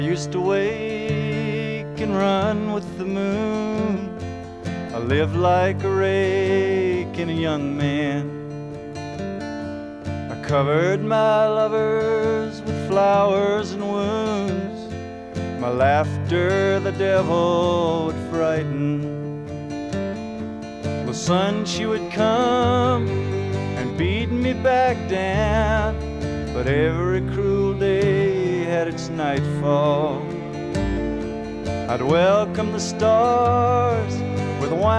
Жанр: Рок / Кантри